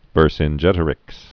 (vûrsĭn-jĕtə-rĭks, -gĕt-) Died 46 BC.